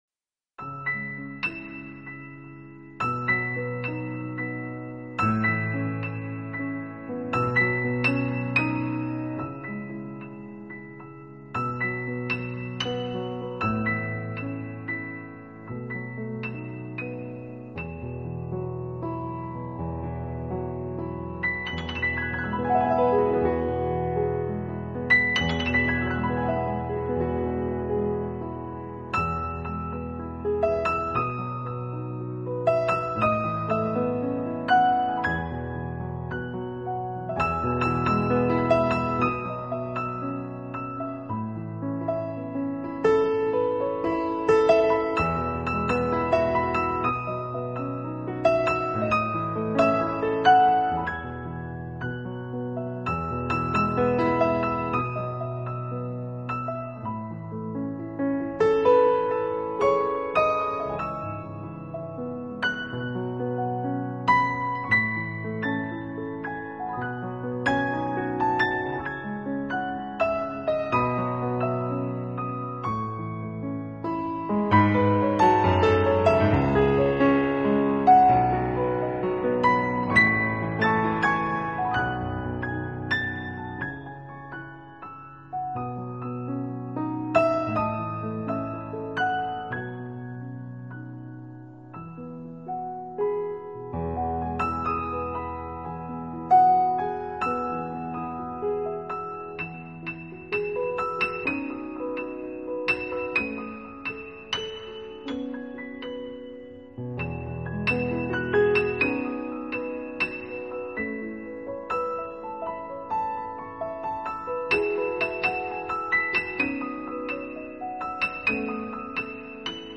【钢琴纯乐】
音乐类型：New Age